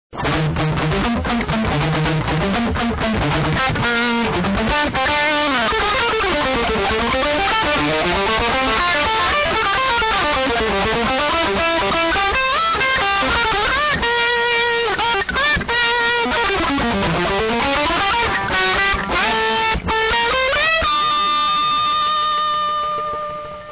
Hear last section    done way out of time and rushed